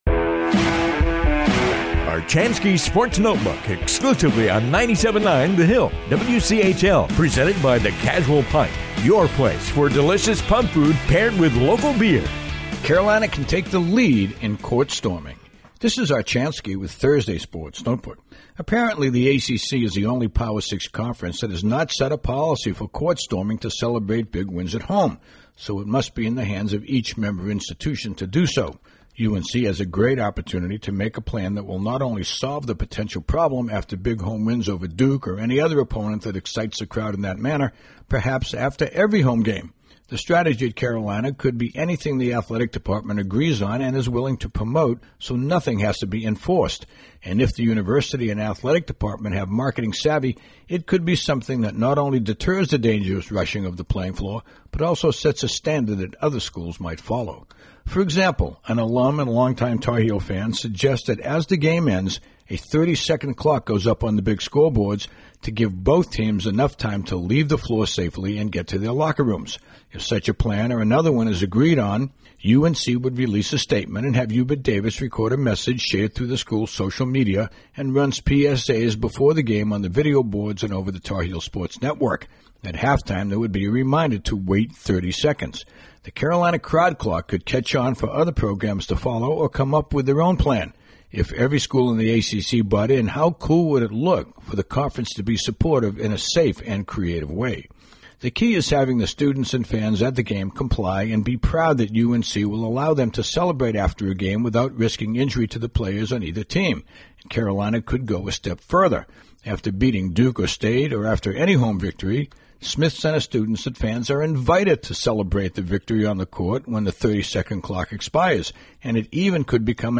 commentary